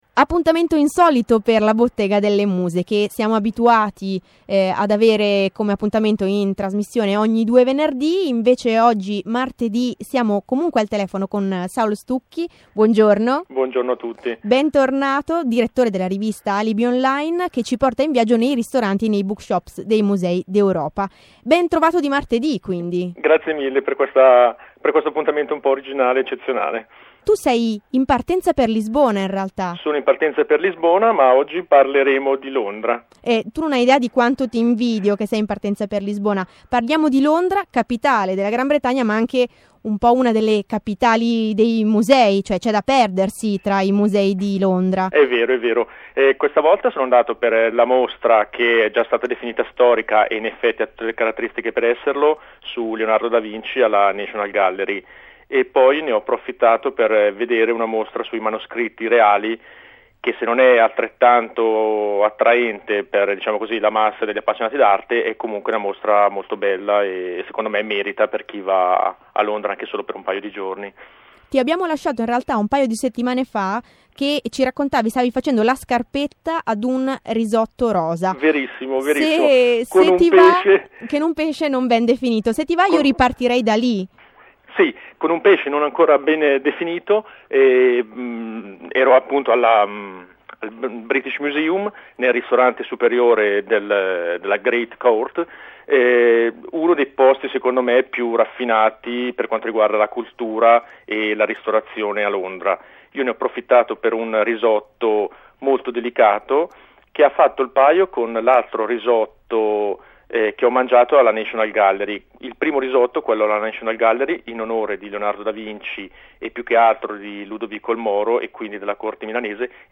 Al ristorante della Great Court del British Museum in attesa del collegamento con Jalla! Jalla! Sul tavolo potete notare il foglio di appunti sulla puntata dedicata a Parigi.